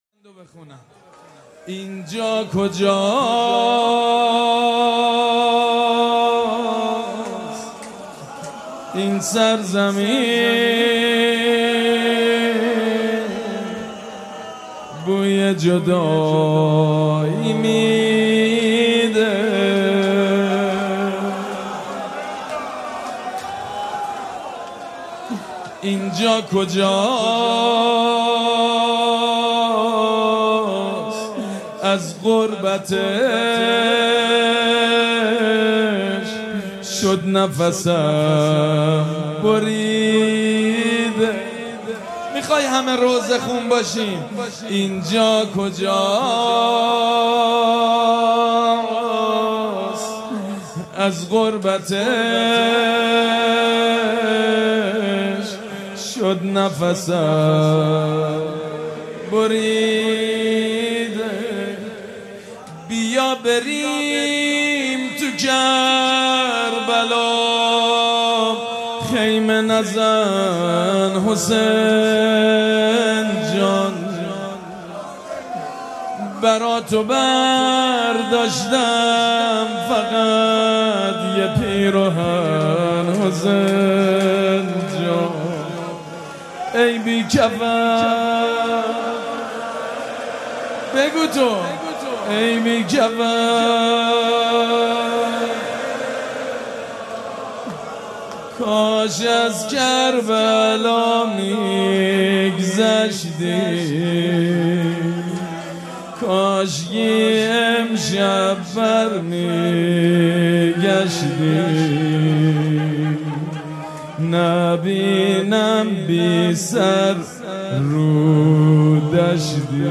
شب دوم محرم الحرام در هیئت ریحانه الحسین علیه السلام
مداحی